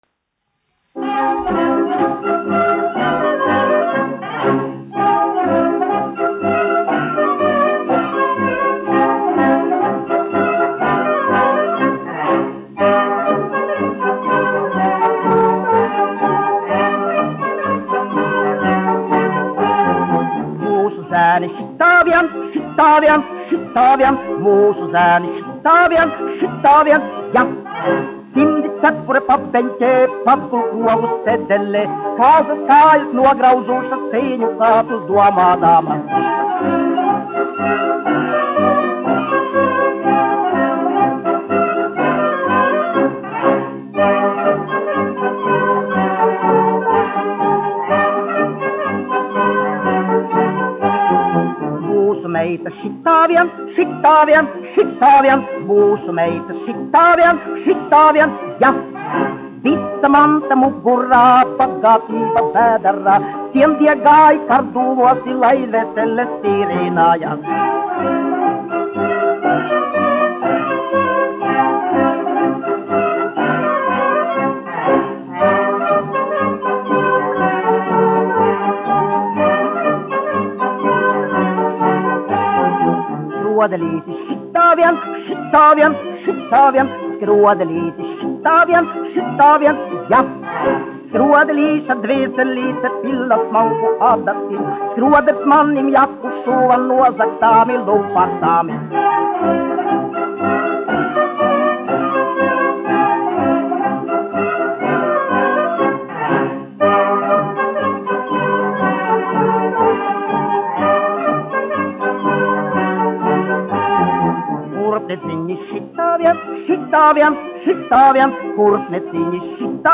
1 skpl. : analogs, 78 apgr/min, mono ; 25 cm
Polkas
Skaņuplate